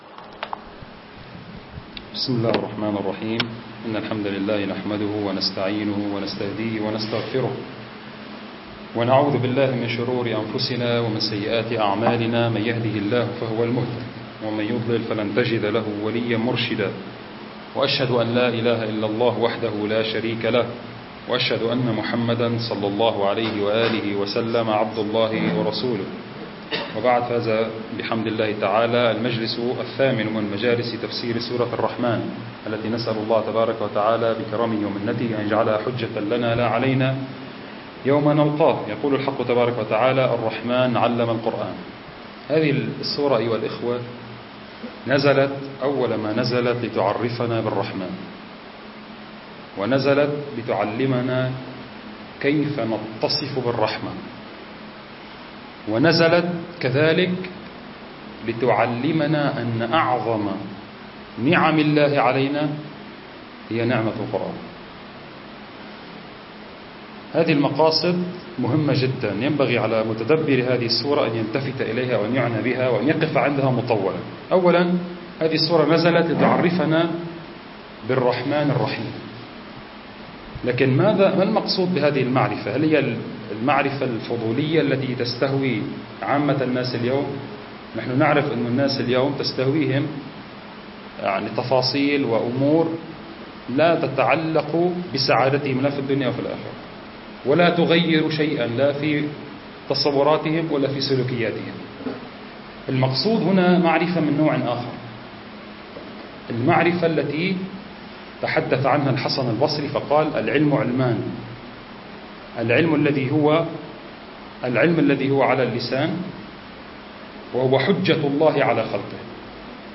المكان: مركز جماعة عباد الرحمن